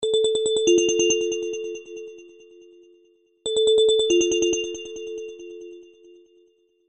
Kategorien: Sms Töne